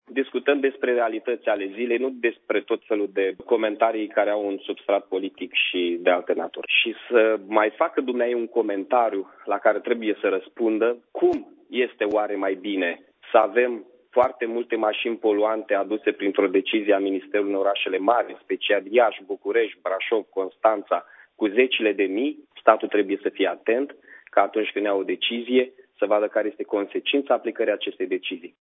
Primarul Iaşului, Mihai Chirica, a mai declarat pentru postul nostru de radio că eliminarea taxei de mediu a dus la creşterea numărului de maşini second hand: